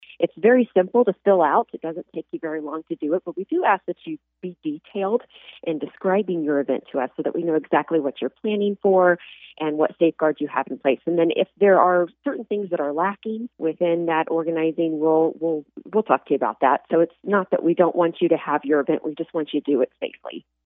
Riley County Health Department Director Julie Gibbs shared details during Monday’s 7 a.m. COVID-19 update on KMAN.